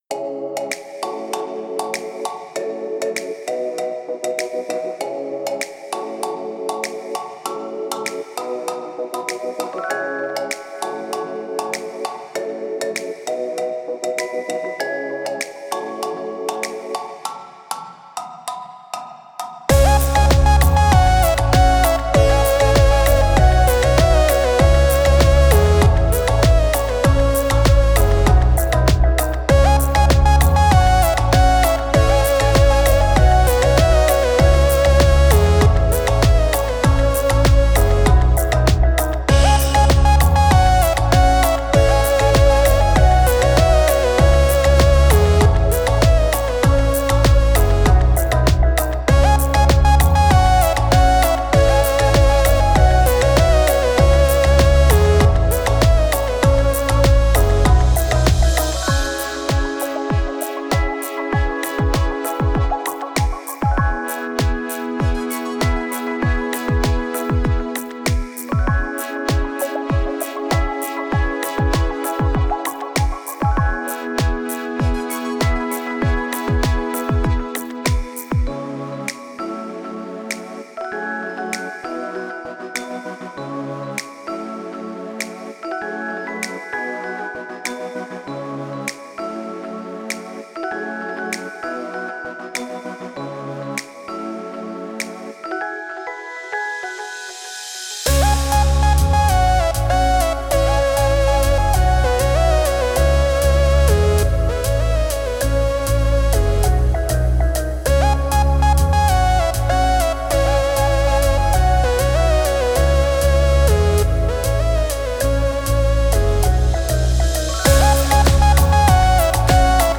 Una buena mezcla y un buen mastering transforman por completo una canción.